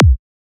edm-kick-51.wav